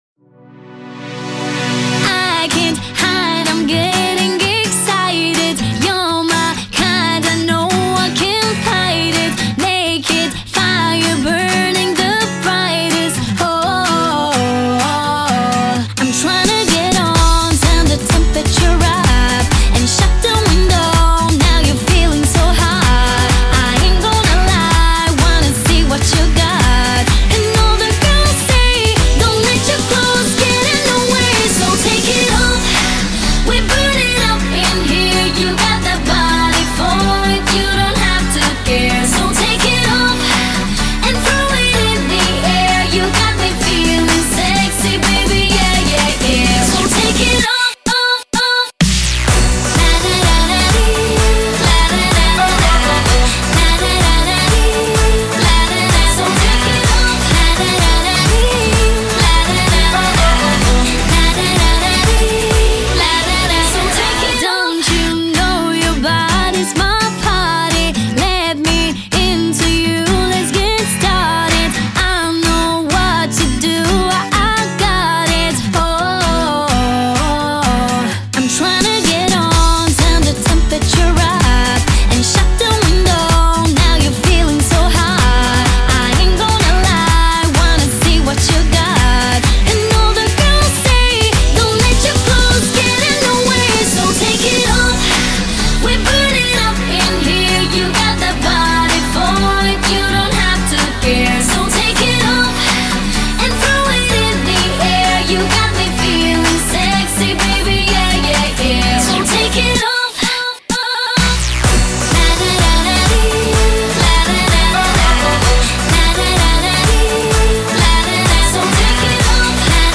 Estilo: Pop